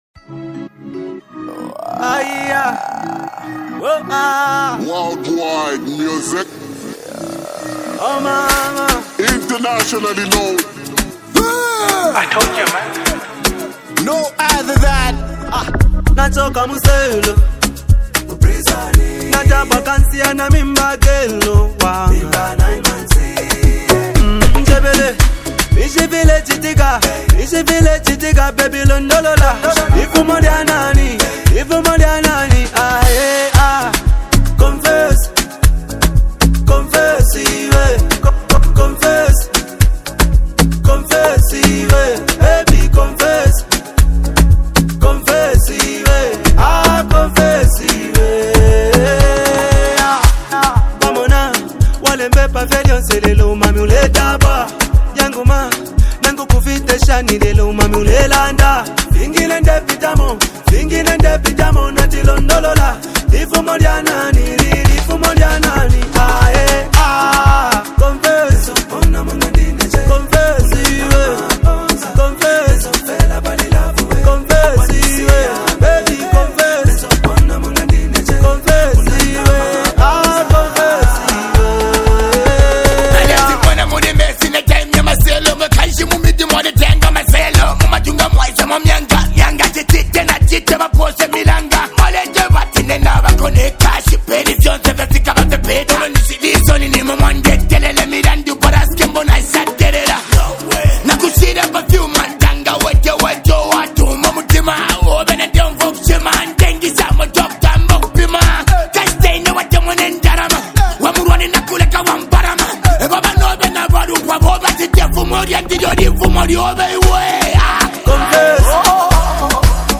Zambian music